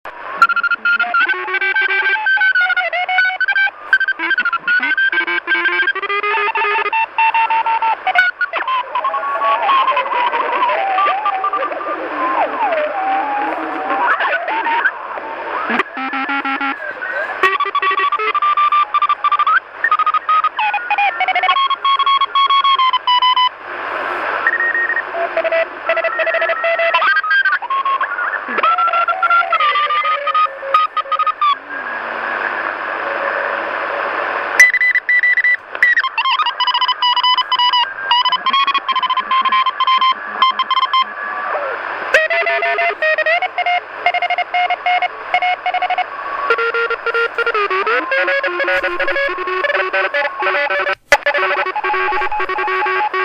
Послушал тест "CQ-WW-CW" на самоделке, только что, на 7 мГц.
Но, сегодня просто прошу послушать работу самоделки на приём в тесте.
Имя файла: RX_Prijatel-20_29.11.2015_7mHz_CQ-ww_test.mp3